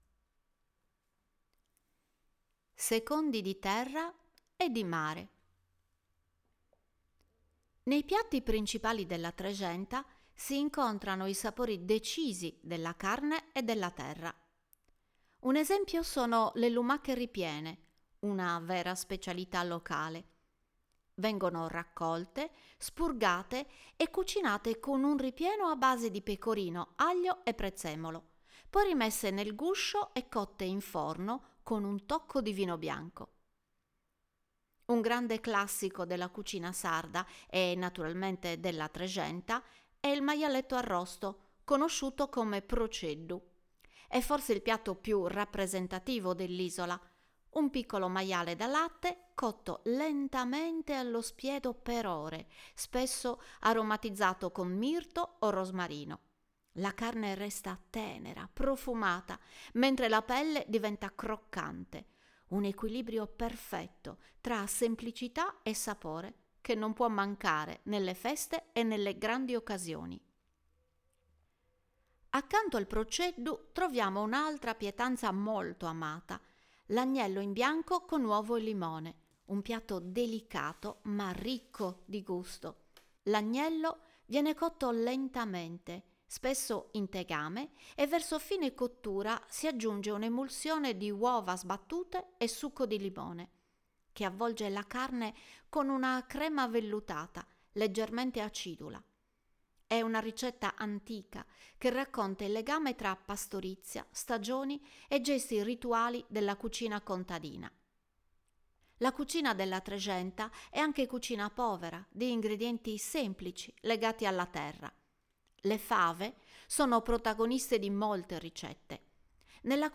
Voce Narrante
🎧 Audioguida - Sapori della Trexenta